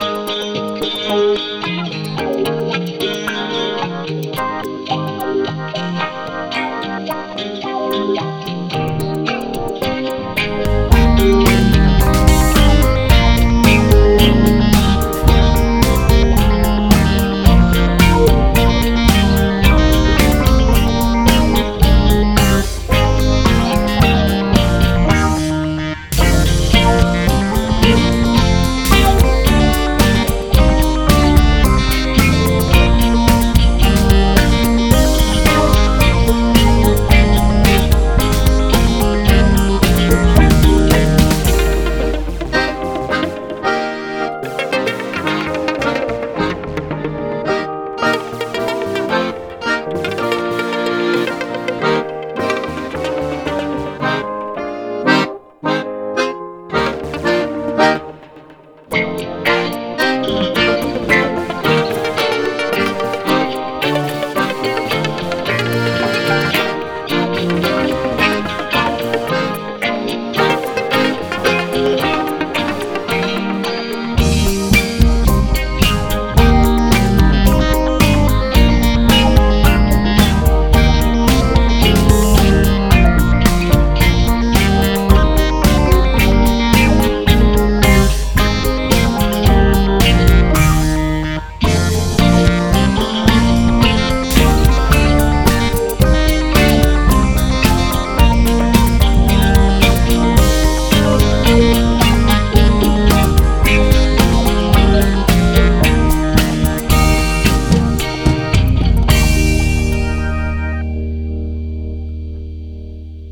Deux instrumentaux